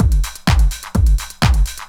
Miltons Beat 2_127.wav